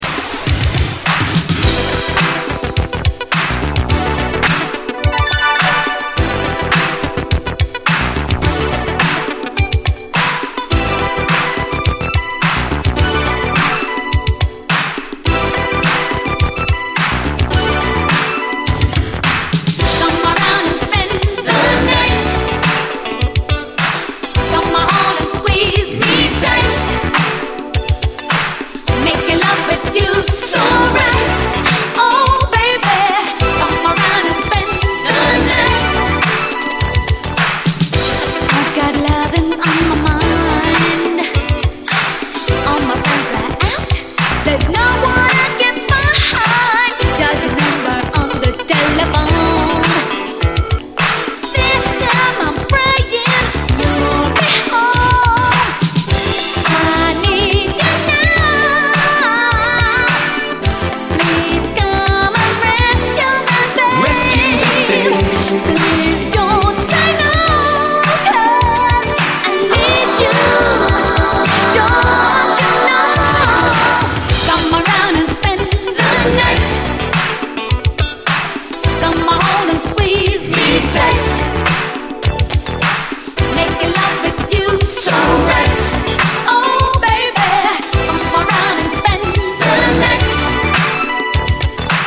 SOUL, 70's～ SOUL, DISCO
キラキラとジューシーなシンセ・ファンク・サウンドのメロウ・ダンサー！